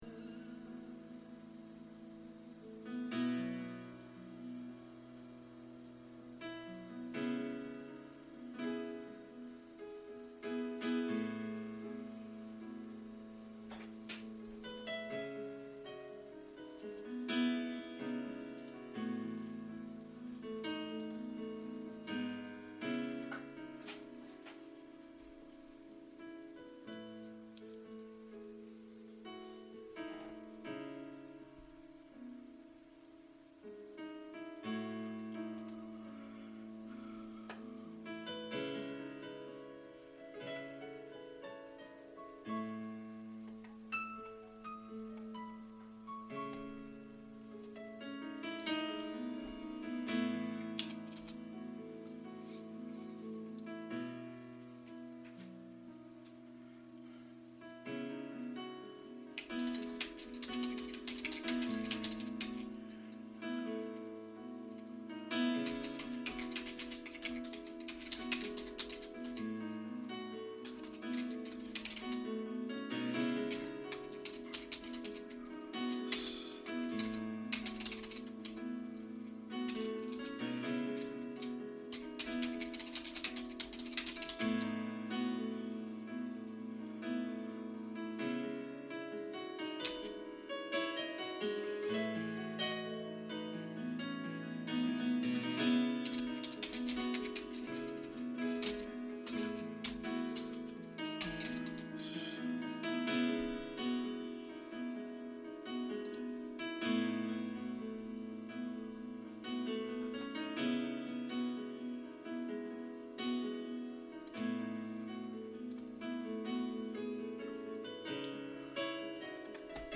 I Surrender All Piano